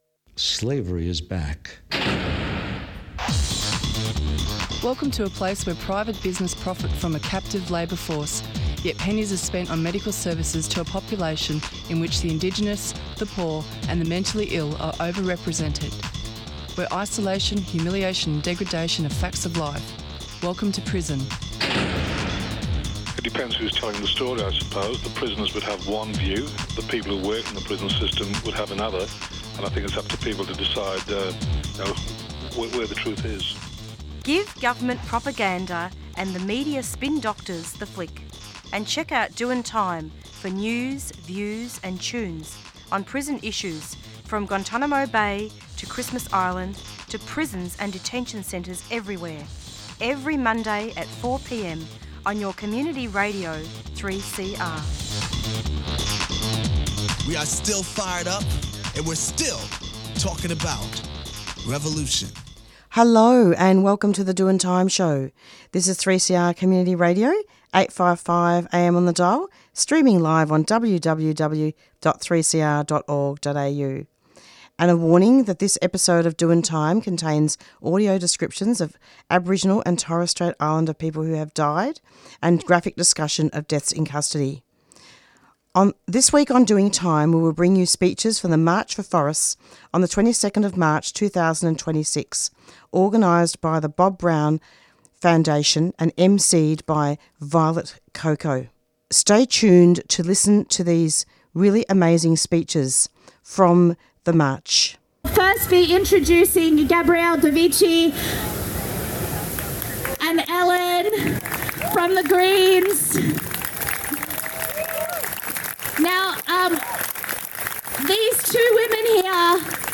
recorded speeches from the March 4 Forests rally